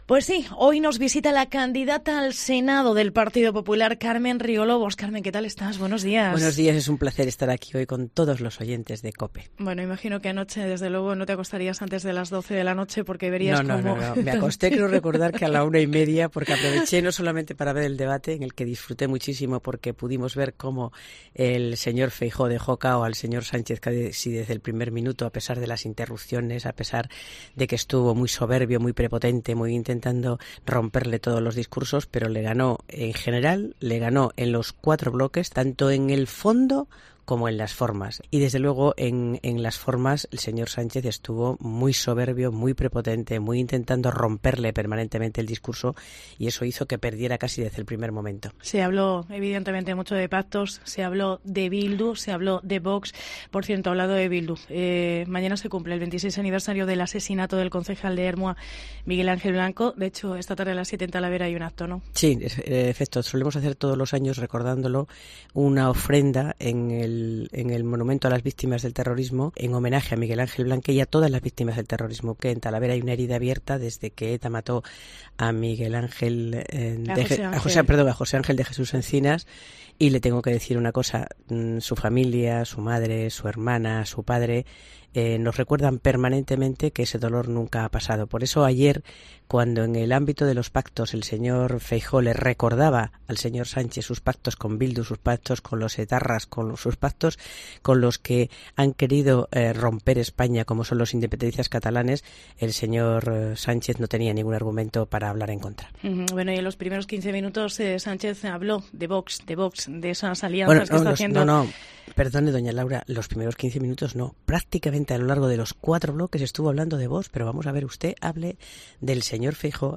Carmen Riolobos en los estudios de COPE Castilla-La Mancha